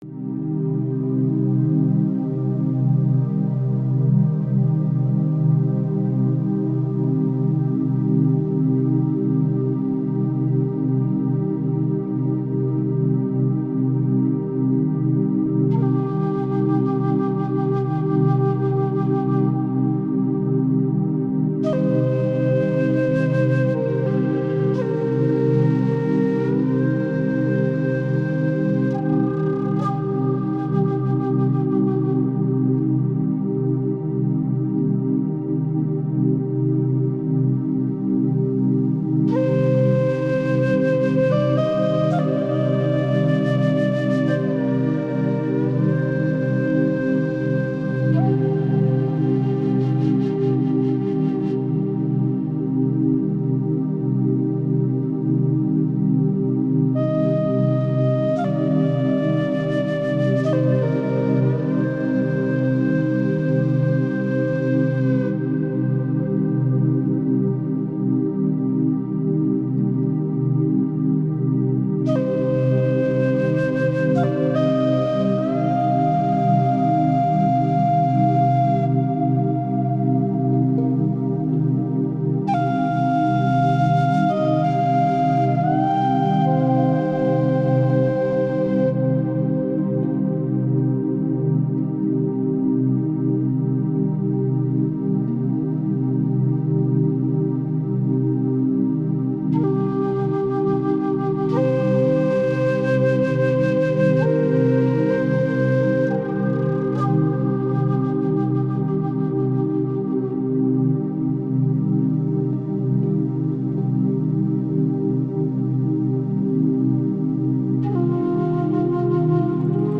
FRIEDLICHE-NACHT-ZAUBER: Diese Klänge beruhigen deinen Geist für sanfteste Träume